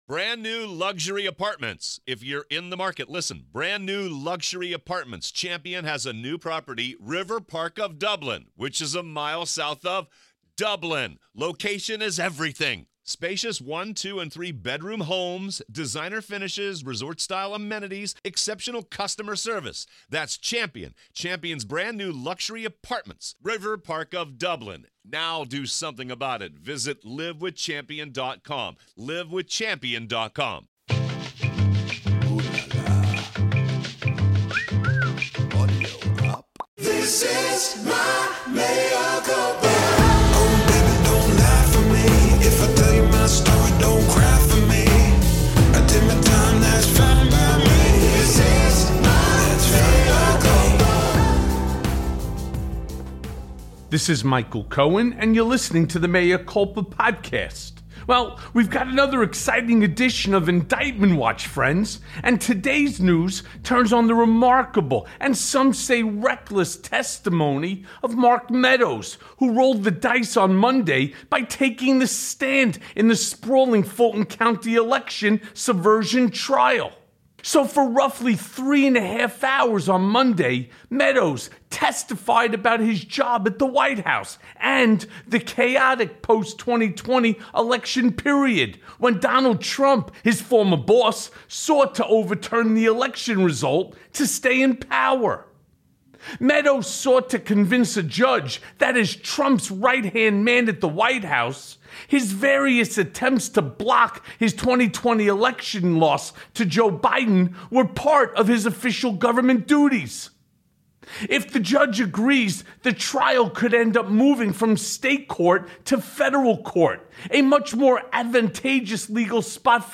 This week on indictment watch we have my friend, mentor, and moral compass Norm Eisen the former Obama Ethics Czar.